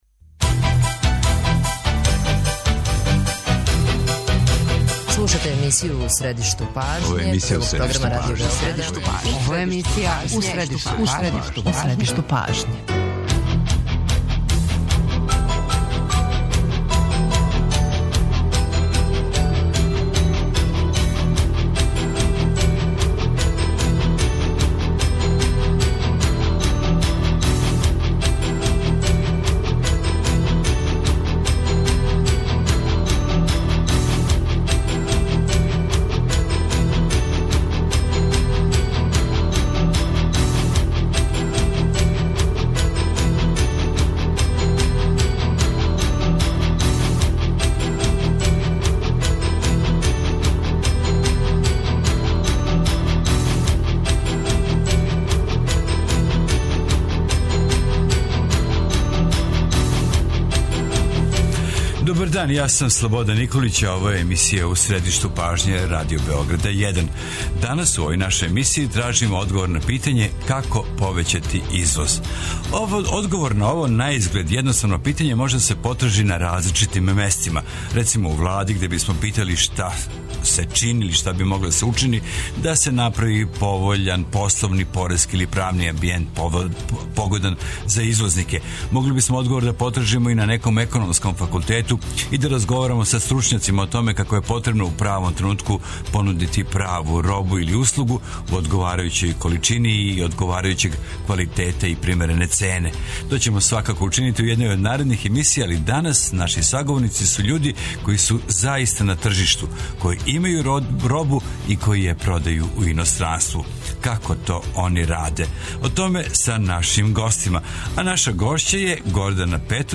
Како тај наизглед једноставан циљ остварити? О томе ће нам говорити извозници који су ових дана били на сајмовима у Русији и Немачкој, као и представници СИЕПЕ.